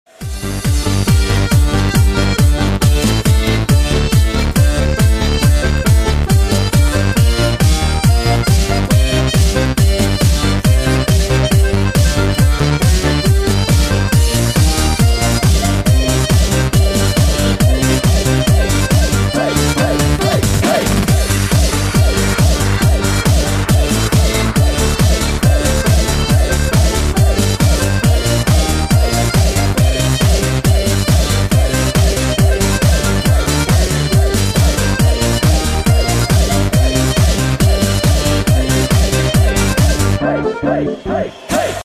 • Качество: 128, Stereo
громкие
без слов
Народные
house
Гармошка
Hard dance